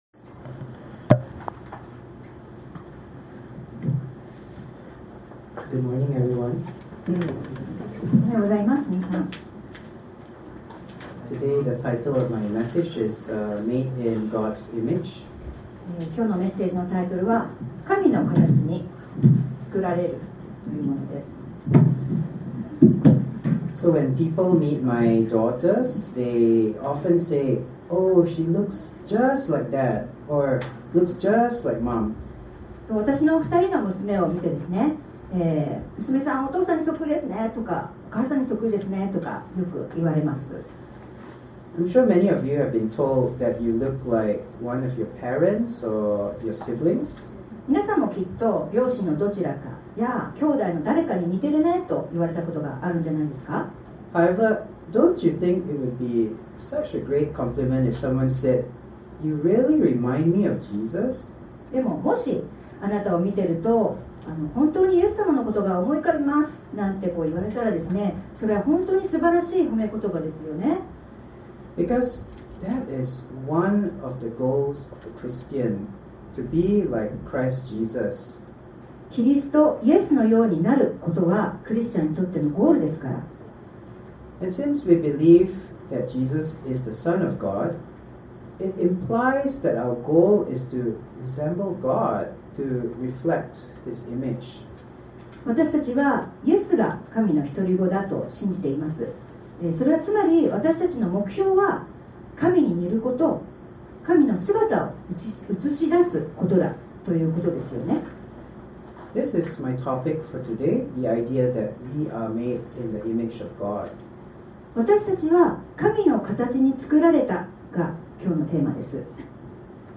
↓Audio link to the sermon: (If you can’t listen on your iPhone, please update your iOS)